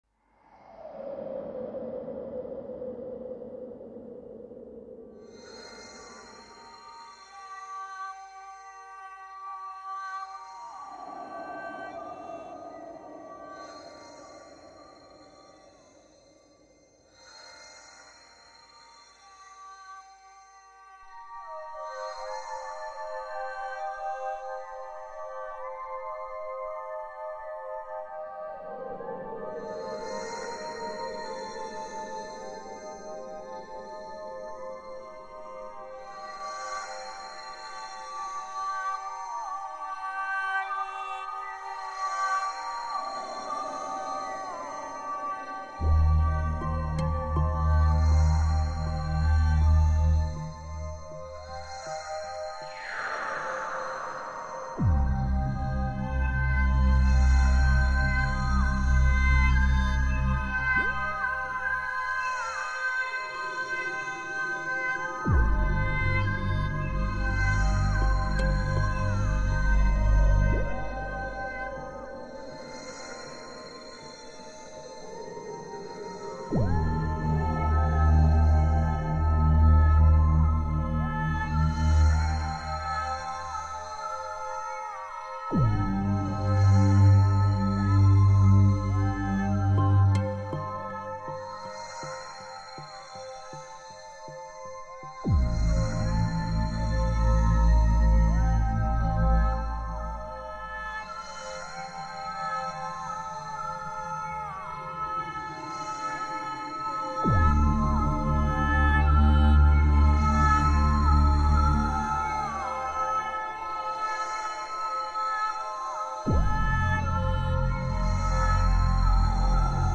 soundtrack slow dreamy